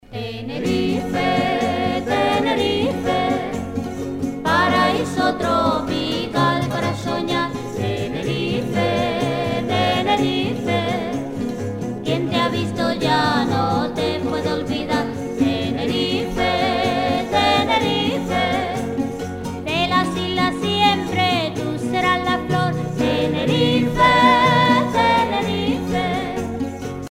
danse : paso-doble
Pièce musicale éditée